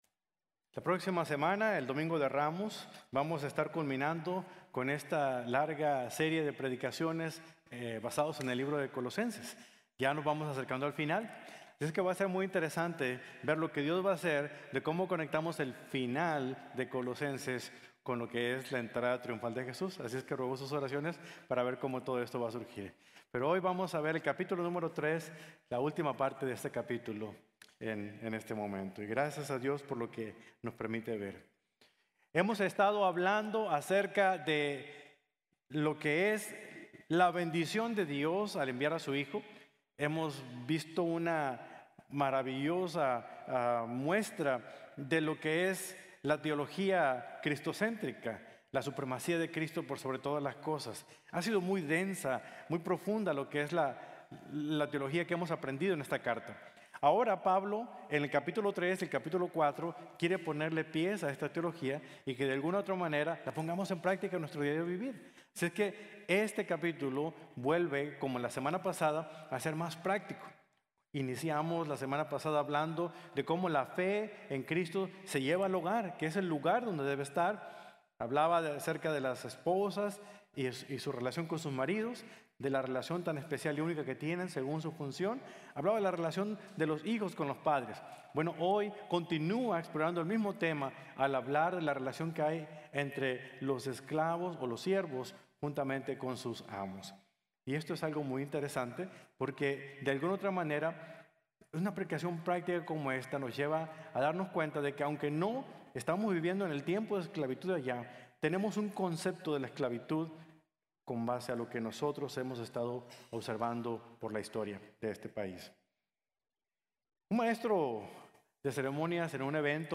Trabajamos para Jesús | Sermón | Iglesia Bíblica de la Gracia